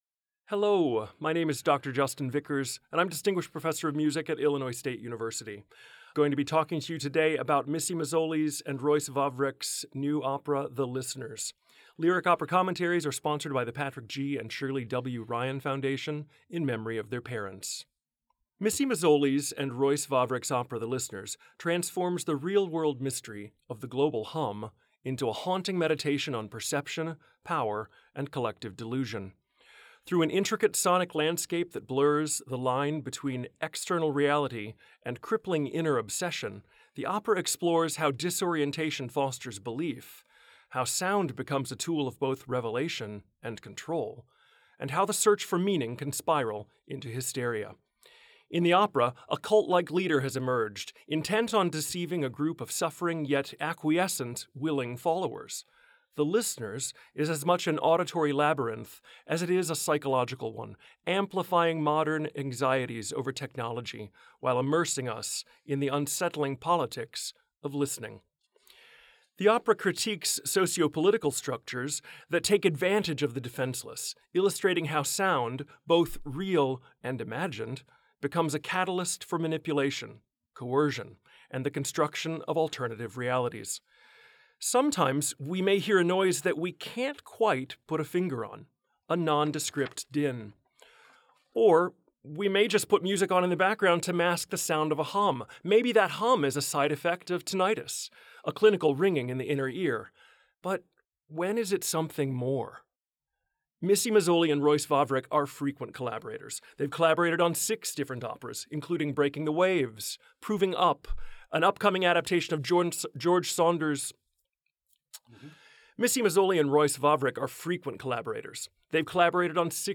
2025_LOC_LISTENERS_COMMENTARY.wav